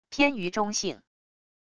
偏于中性wav音频